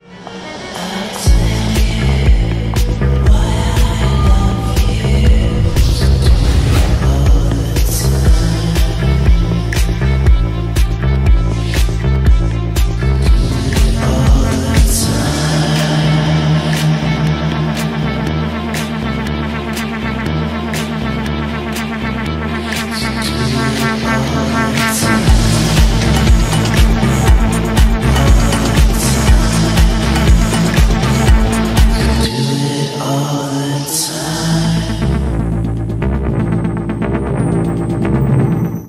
• Качество: 128, Stereo
атмосферные
Electronica
Downtempo
чувственные
мрачные